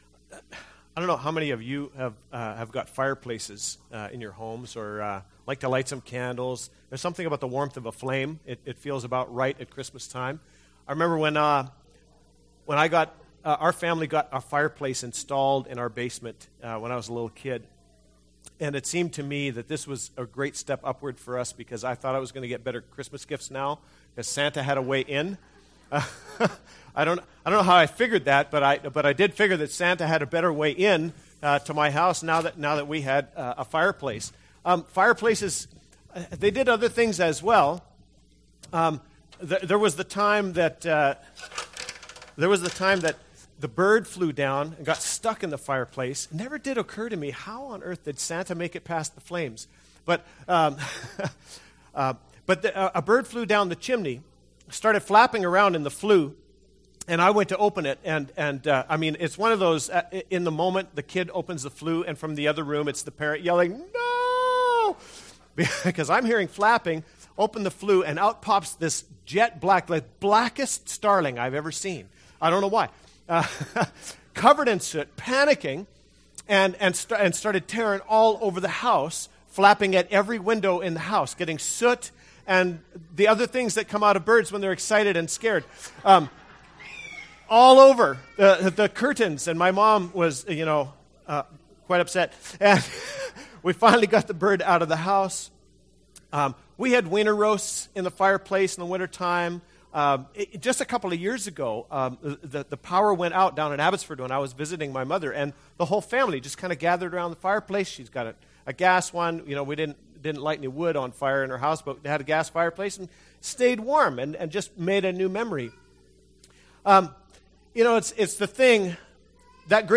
Christmas Eve Service | Williams Lake Alliance Church